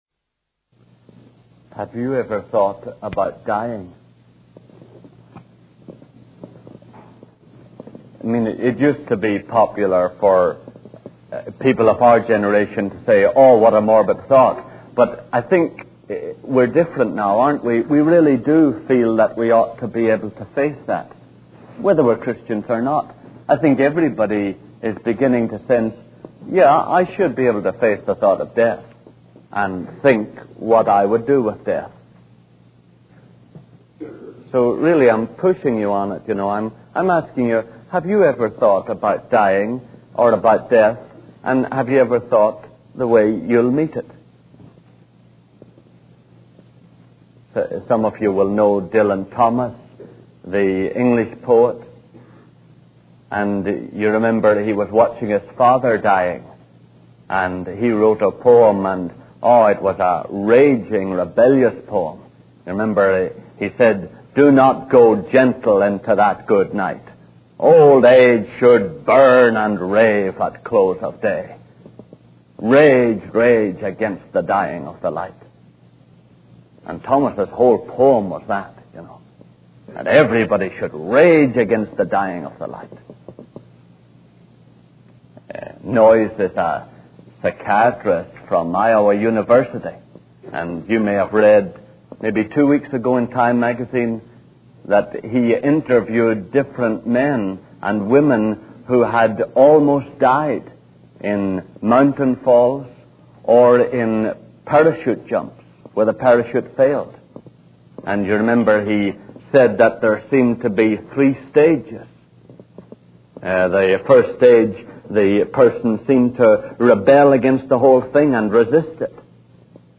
In this sermon, the preacher discusses the stages that people who have faced near-death experiences go through. He mentions that initially, individuals tend to resist and rebel against the idea of death.